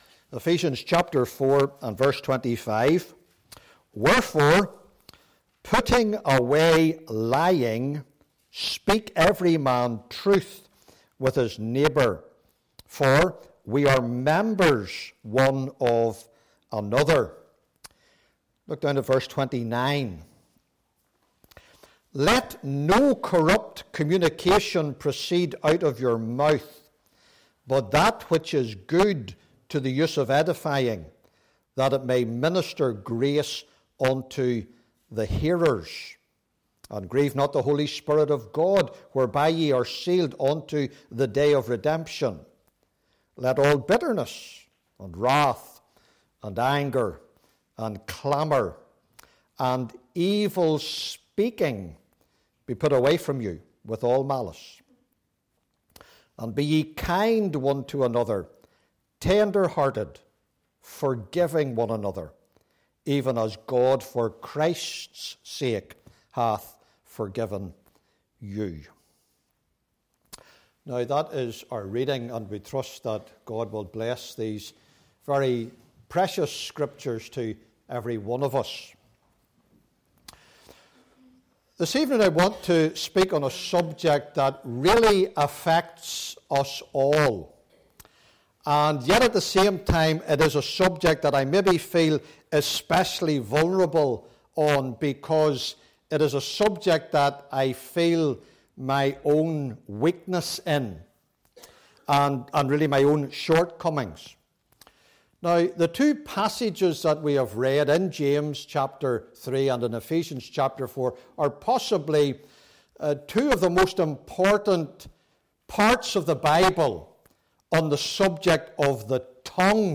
Meeting Type: Ministry
Topics: Exposition « The Stewardship of the Assembly: Shepherding Well He Careth For You!